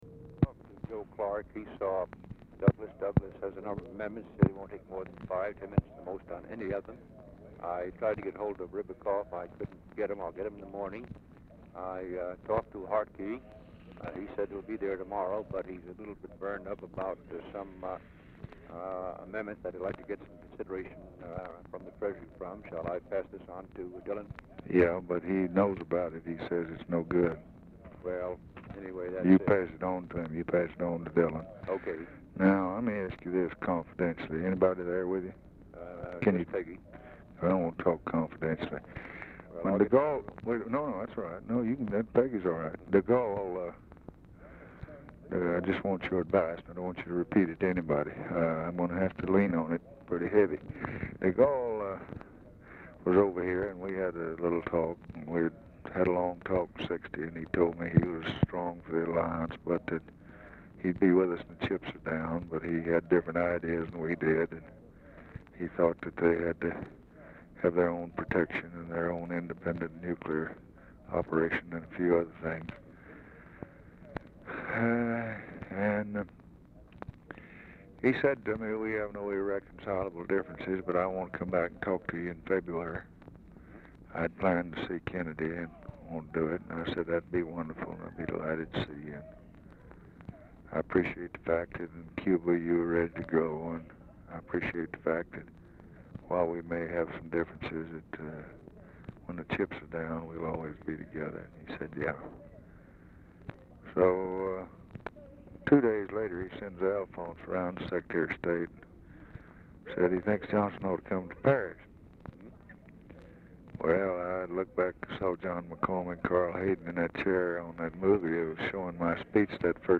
Telephone conversation # 1258, sound recording, LBJ and MIKE MANSFIELD, 1/8/1964, 6:15PM | Discover LBJ
Format Dictation belt
Location Of Speaker 1 Oval Office or unknown location